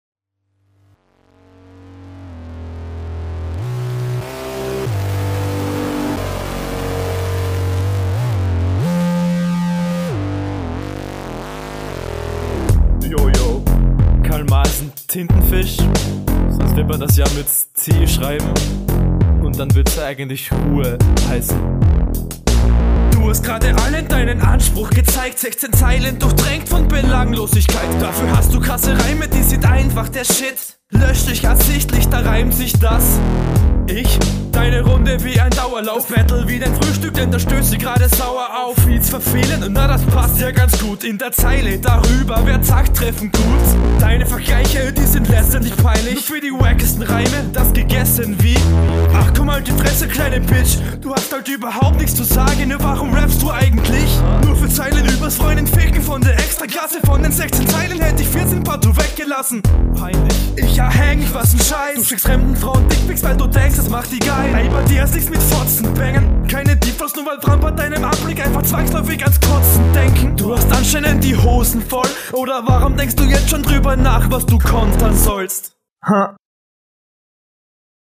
Du betonst ebenfalls sehr unterhaltsam und sehr passend zu deinen Punchlines/Kontern!
Du hast eine etwas höhere, "schrillere" Stimme, …
Quali ist kann leider nicht mithalten, dafür konterst du gut aus und hast coole flowansätze …